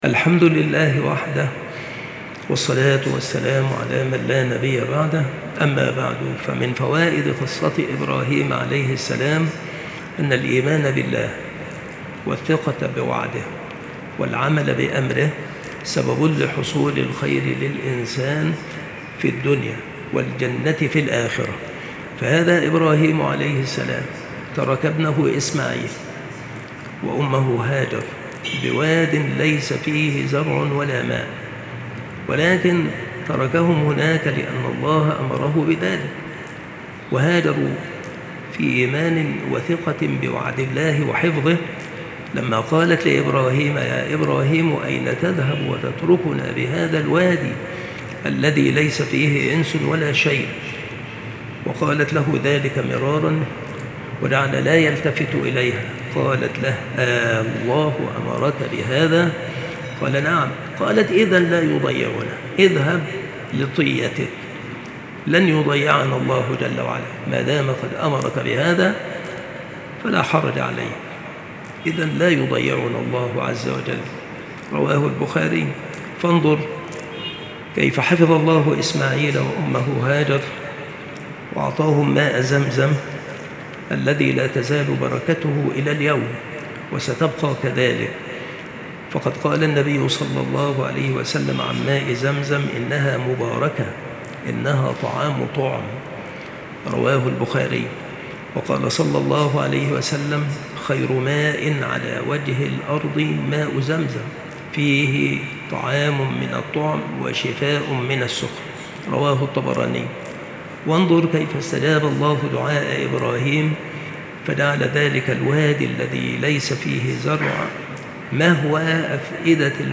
بالمسجد الشرقي - سبك الأحد - أشمون - محافظة المنوفية - مصر